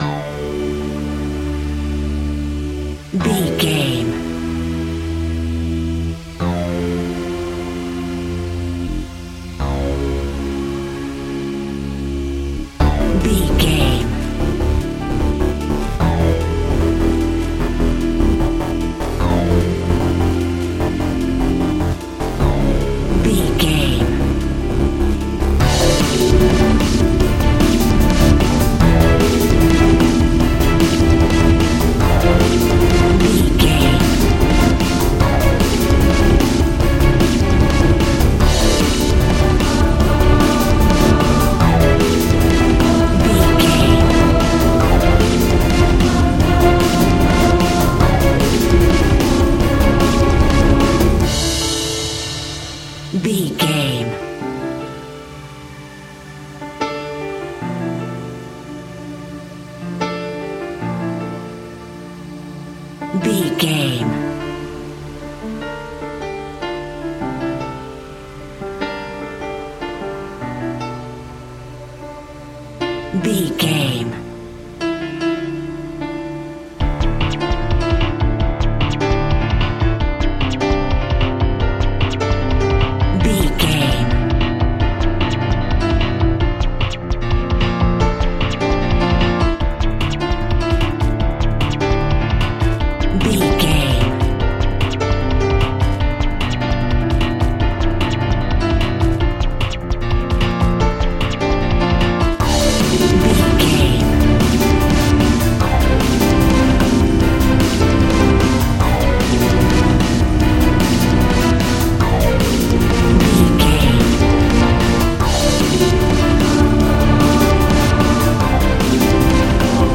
In-crescendo
Aeolian/Minor
Fast
tension
ominous
dark
dramatic
eerie
synthesiser
piano
drums
strings
suspenseful